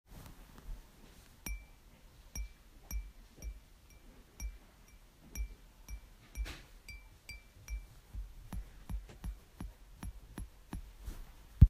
glass_walk.ogg